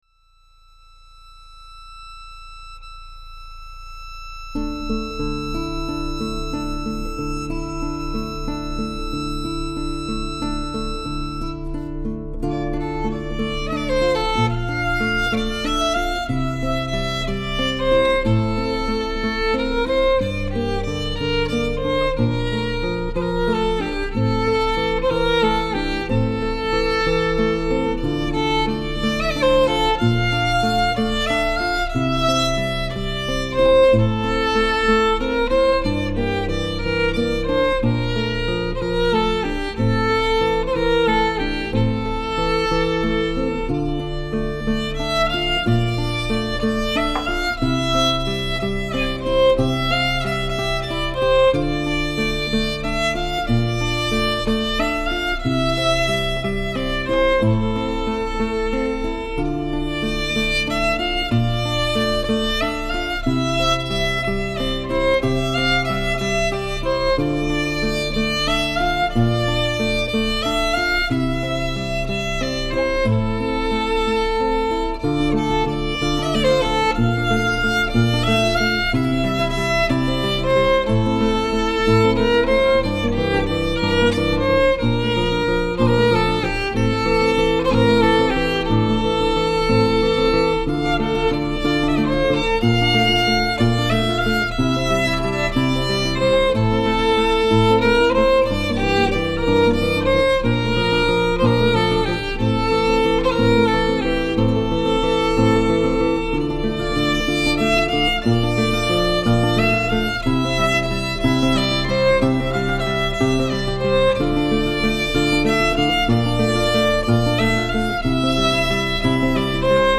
violon